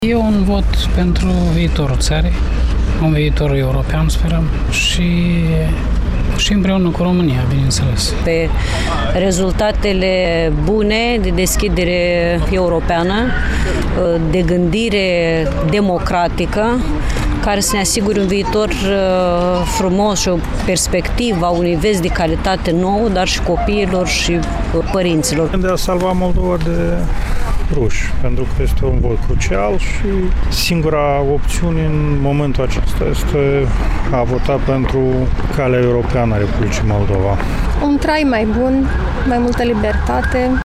Cea mai aglomerată secție rămâne cea de la Casa de Cultură a Studenților.
28-sept-ora-15-vox-pop.mp3